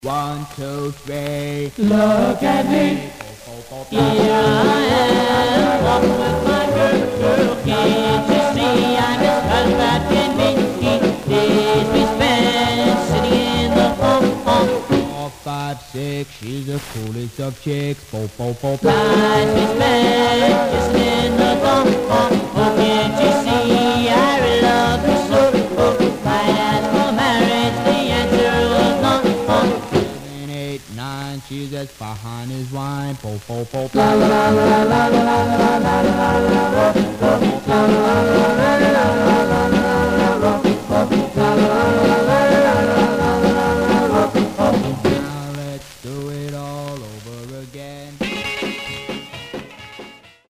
Much surface noise/wear
Mono